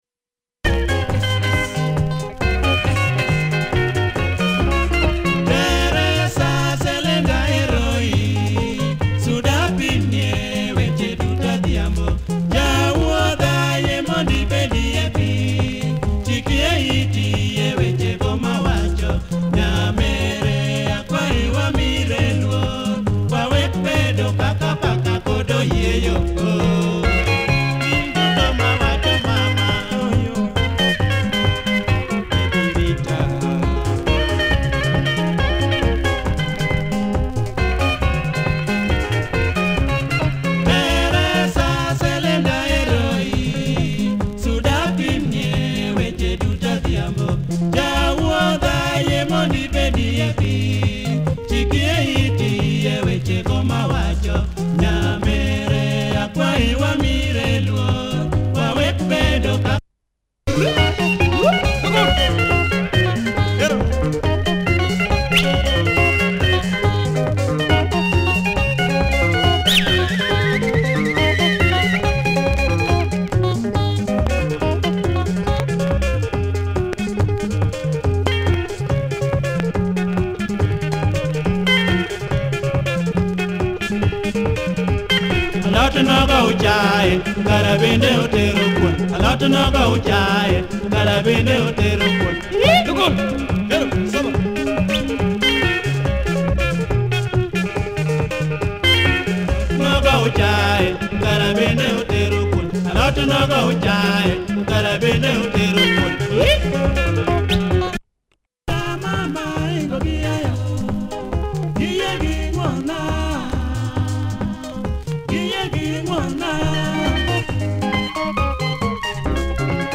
Nice LUO benga